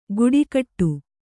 ♪ guḍikaṭṭu